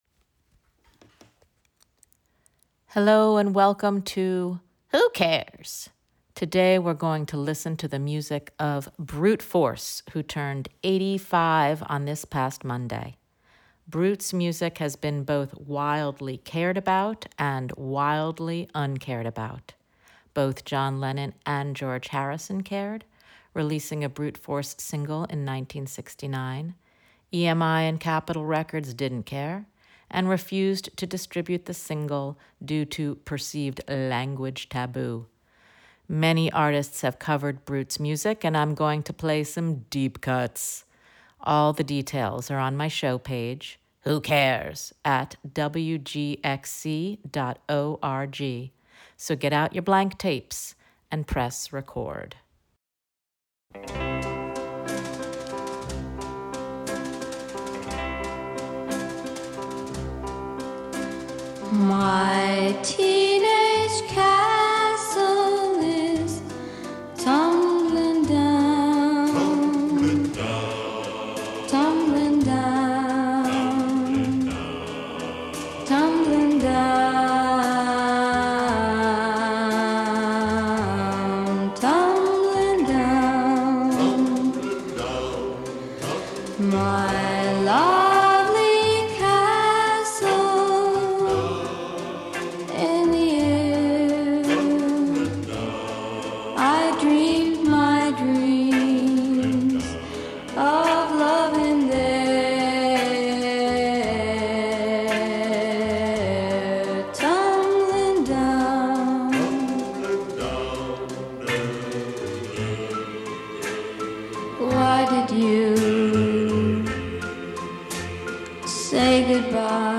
is an interview-based segment that is a mini-deep dive into the world of care, what we care about...and maybe why.